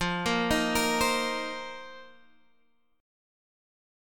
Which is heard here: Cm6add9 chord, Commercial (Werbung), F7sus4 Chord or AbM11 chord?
F7sus4 Chord